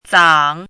怎么读
zǎng
zang3.mp3